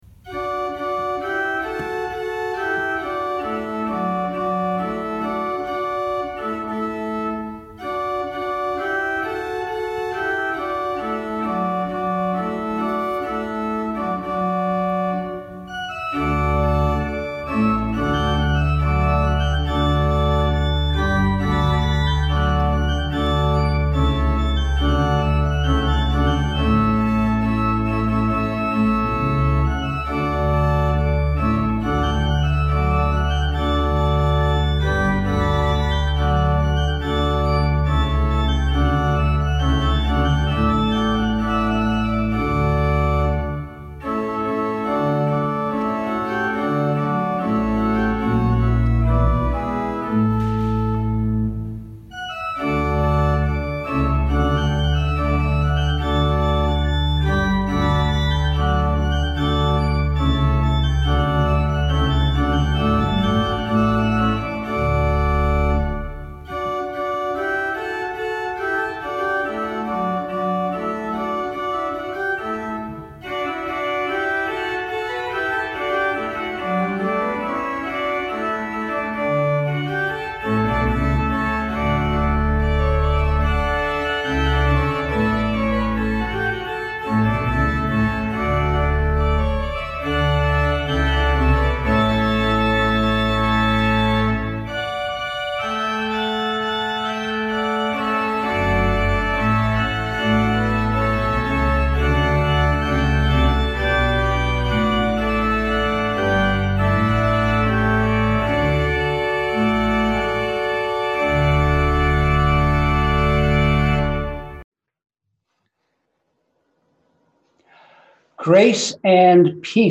We held virtual worship on Sunday, May 3, 2020!
Virtual Worship – 5/3/2020